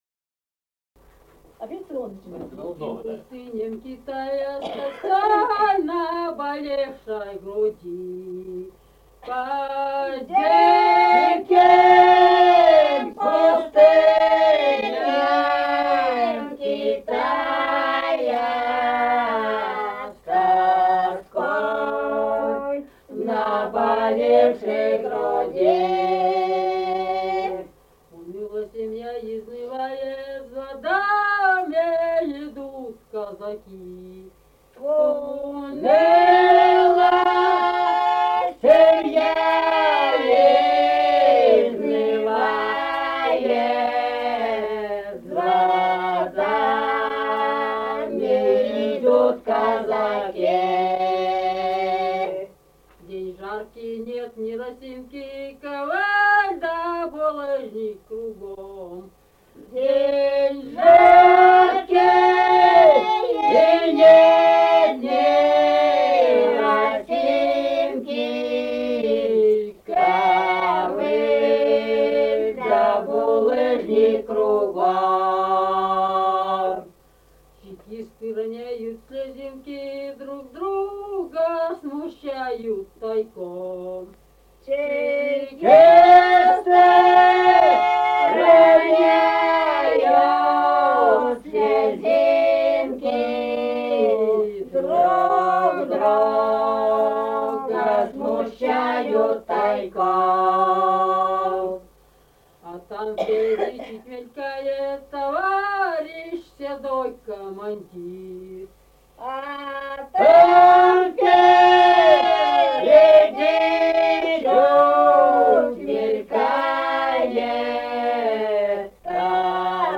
Русские песни Алтайского Беловодья 2 «По диким пустыням Китая», казачья песня периода Гражданской войны.
Республика Казахстан, Восточно-Казахстанская обл., Катон-Карагайский р-н, с. Урыль (казаки), июль 1978.